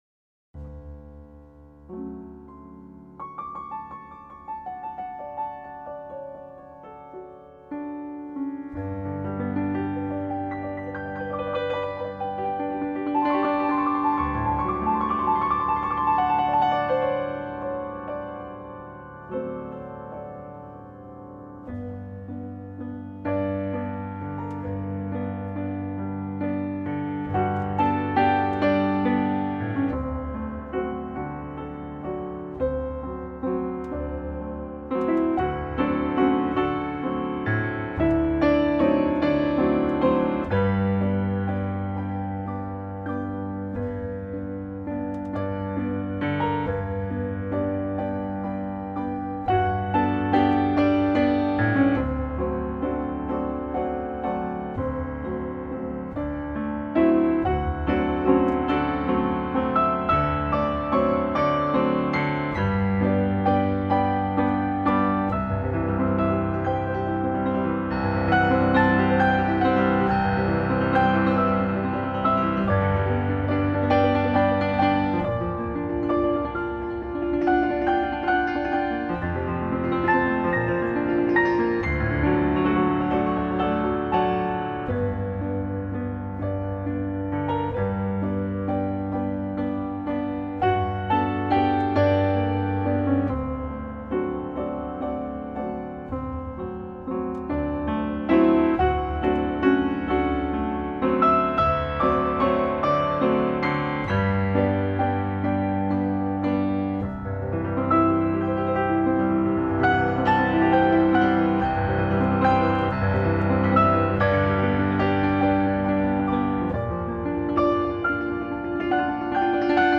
piano6-7.mp3